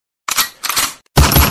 Som de notificação celular tiro de pistola
Toque de Tiro para Celular Barulho de Tiro para Dar Susto
Descrição: Experimente o som de tiro de pistola para celular!
som-de-notificacao-celular-tiro-de-pistola-pt-www_tiengdong_com.mp3